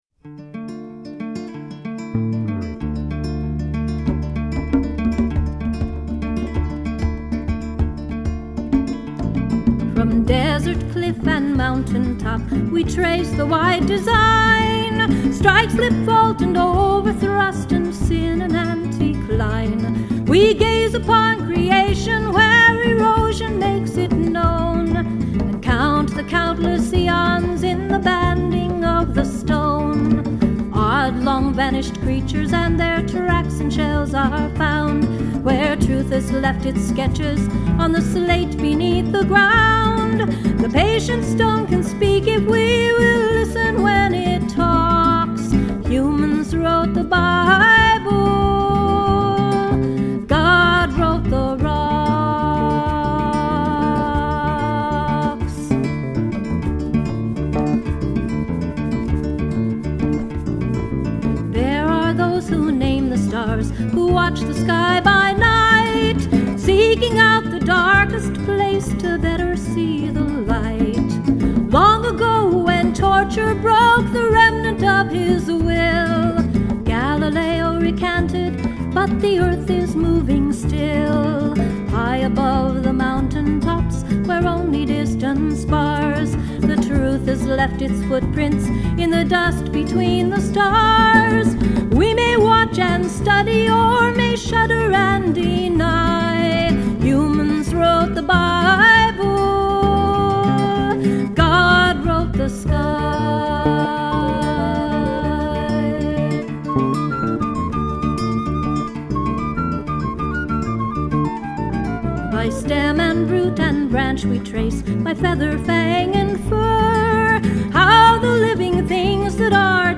filk song